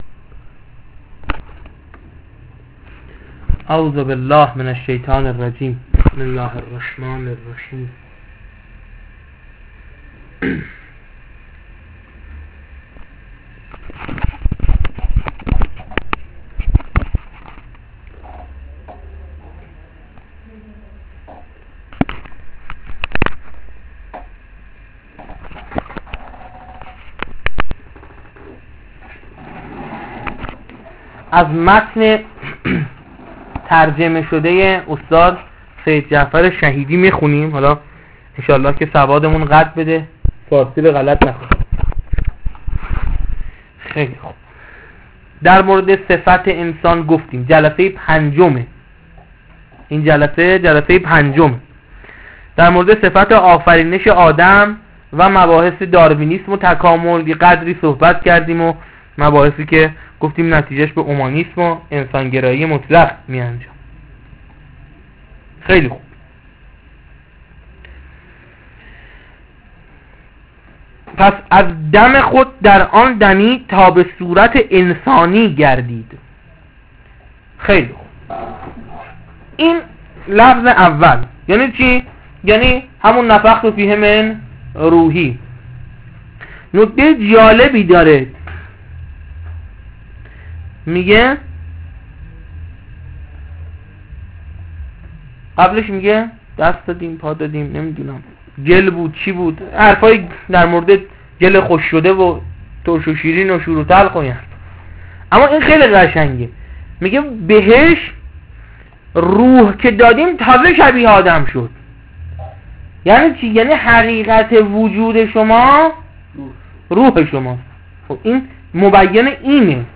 خطبه اول-بخش پنجم